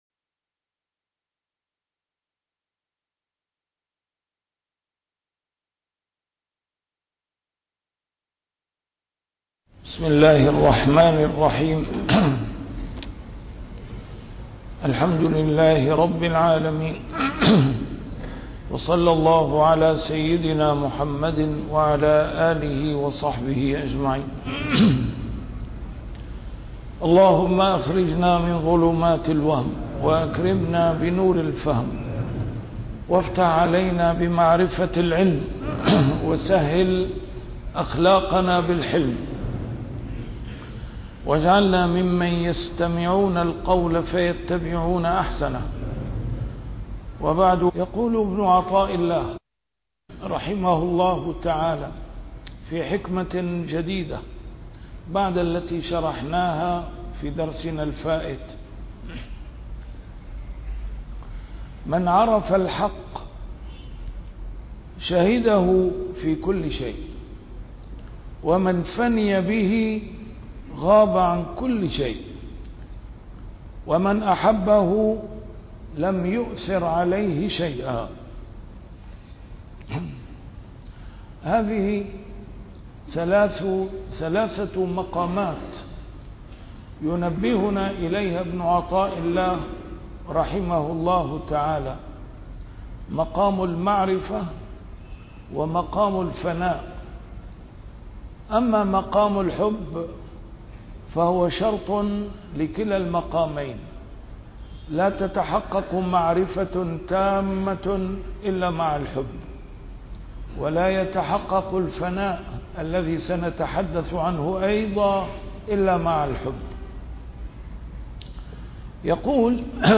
A MARTYR SCHOLAR: IMAM MUHAMMAD SAEED RAMADAN AL-BOUTI - الدروس العلمية - شرح الحكم العطائية - الدرس رقم 186 شرح الحكمة 163